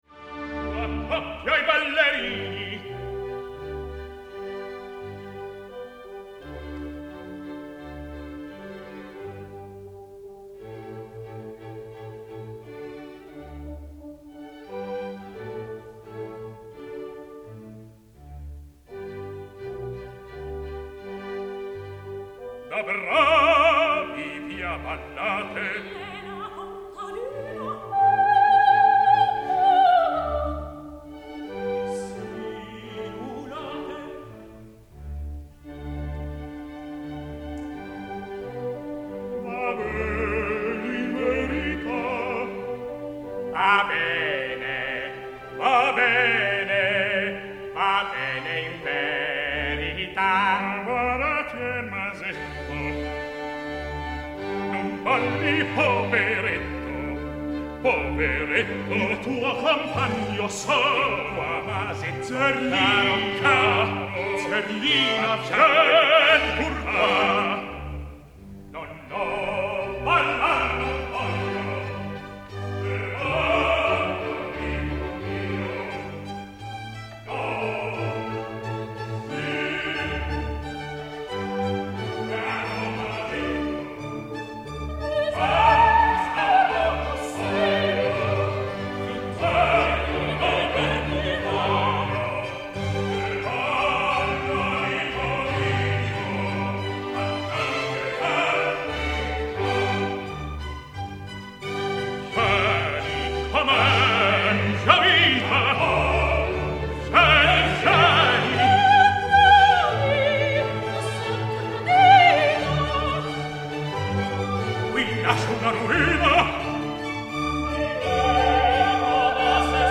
party scene from Act I of Don Giovanni, with dances going in three meters at once, is quite audibly metametric.